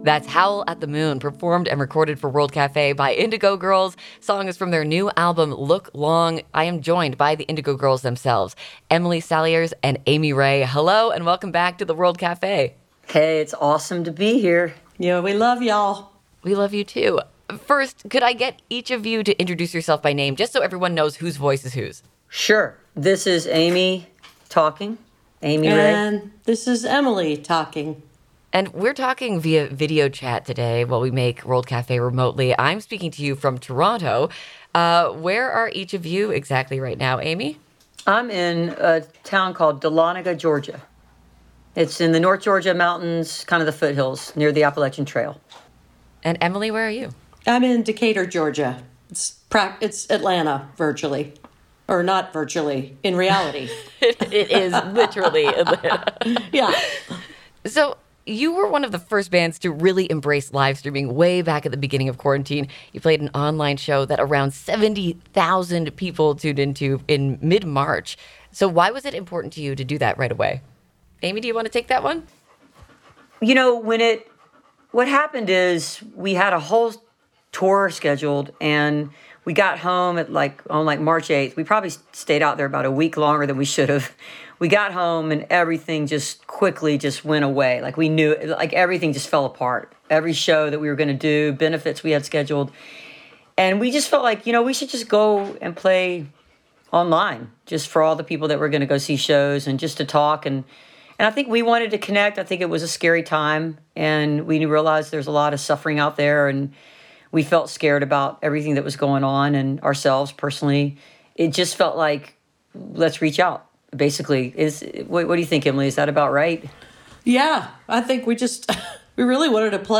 (captured from webcast)
03. interview (5:32)